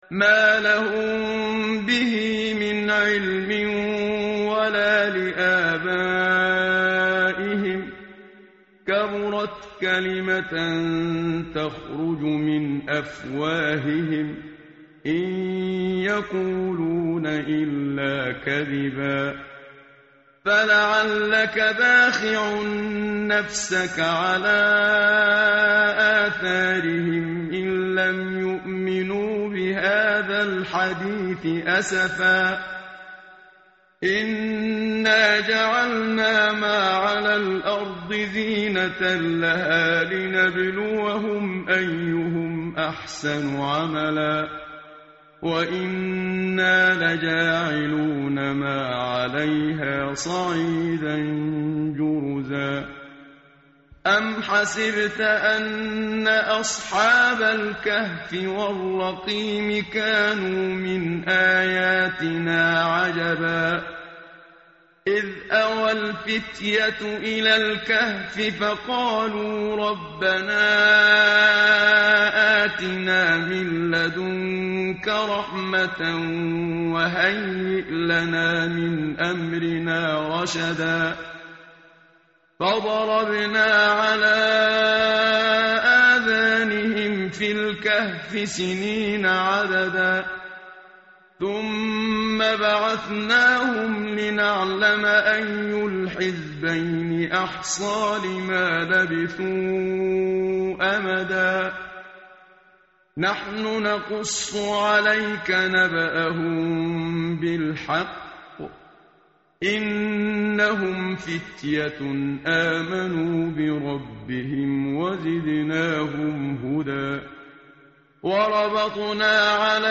tartil_menshavi_page_294.mp3